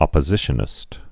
(ŏpə-zĭshə-nĭst)